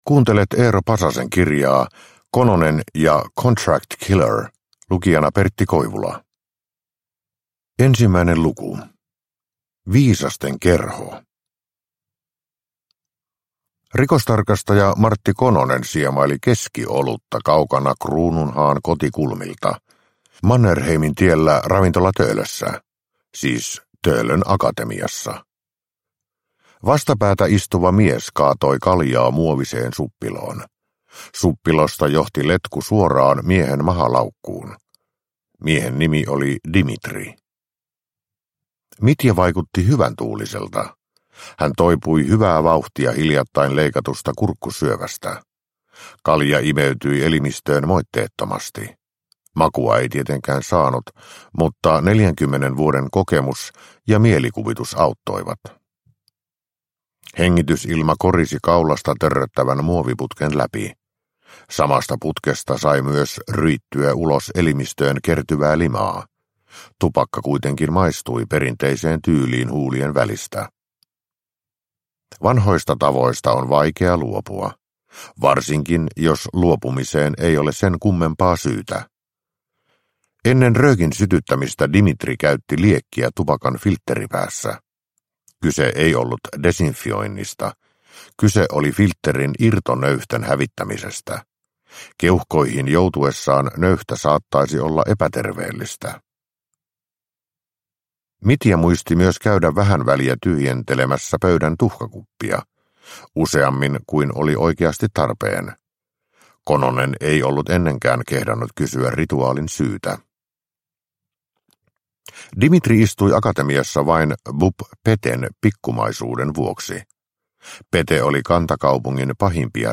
Kononen ja contract killer – Ljudbok – Laddas ner